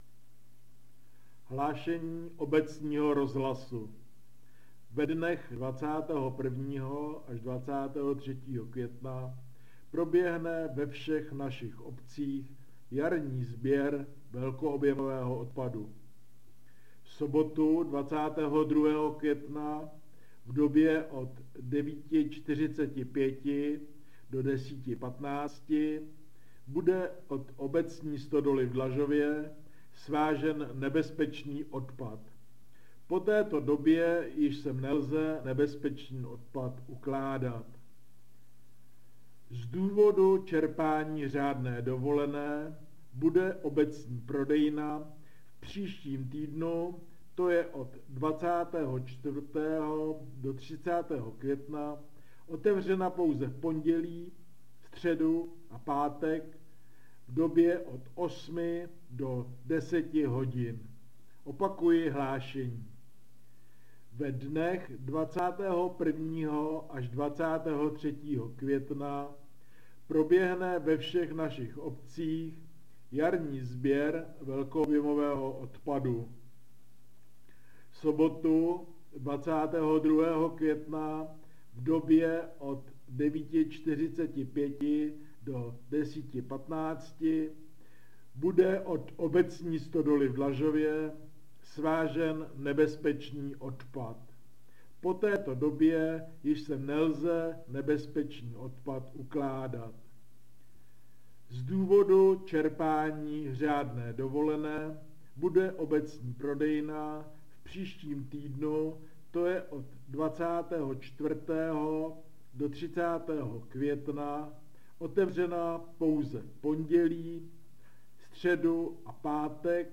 Hlášení obecního rozhlasu - Jarní sběr velkoobjemového a nebezpečného odpadu a přechodné omezení prodejní doby prodejny v Dlažově